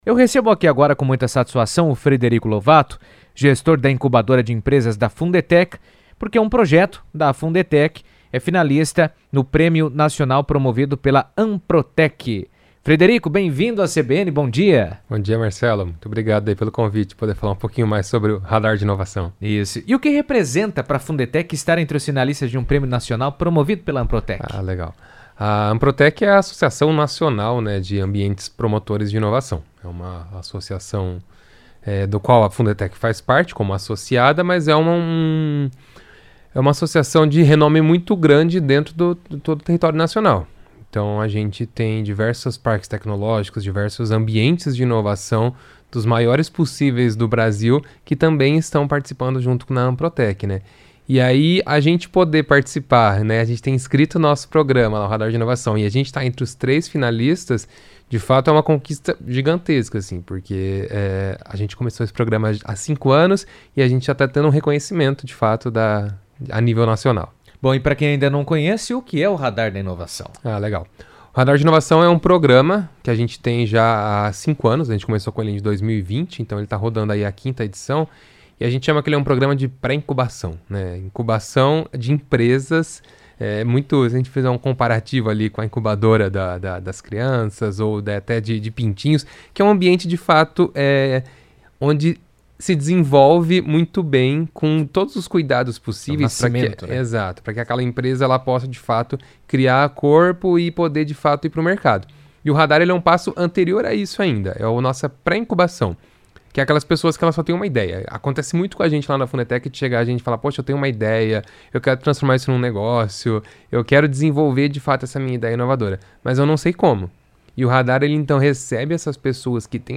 esteve na CBN falando sobre a importância do reconhecimento e os impactos positivos do programa no ecossistema local de inovação.